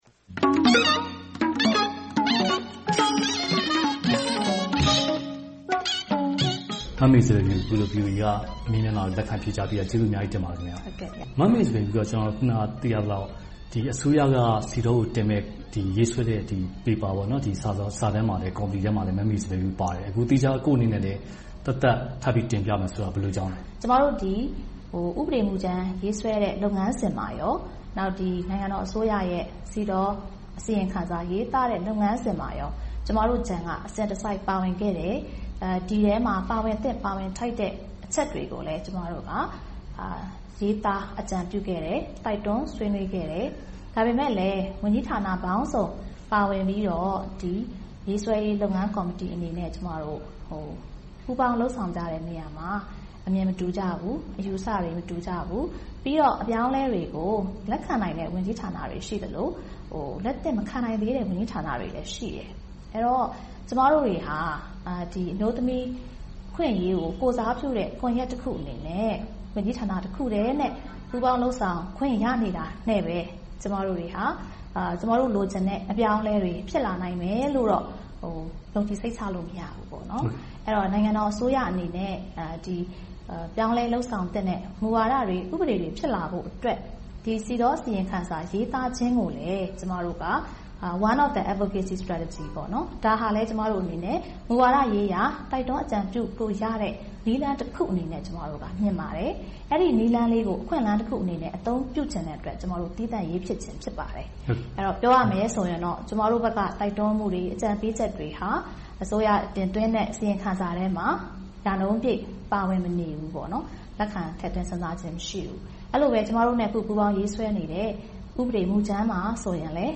တွေ့ဆုံမေးမြန်းထားပါတယ်